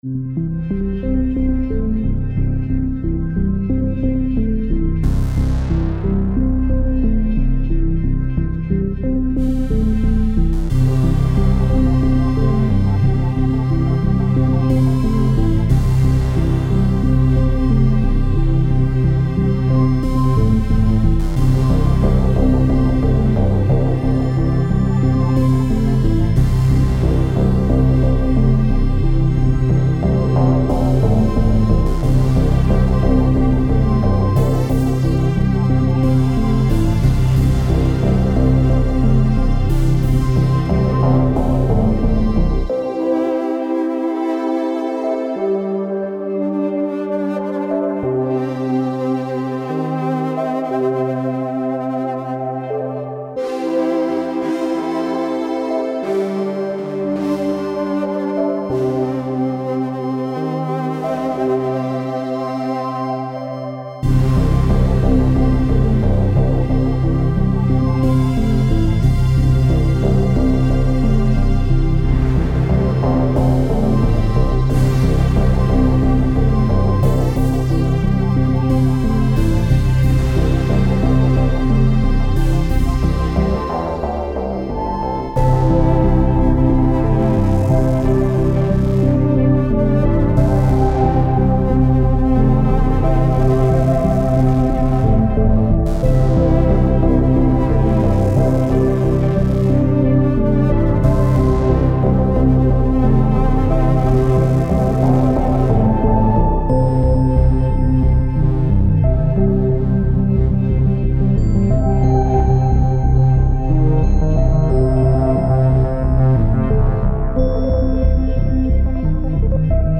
Genre:Synthwave
これはグラミー賞受賞のエンジニア兼プロデューサーによる、進化するシンセアトモスフィアのアンビエントコレクションです。
ヘビーなアナログシンセ、豊かなパッド、進化するFXを特徴とし
スイープ、ベル、リード、ブラス、チャイム、シーケンスがすべてシンセティックな未来に向けて準備されています。
デモサウンドはコチラ↓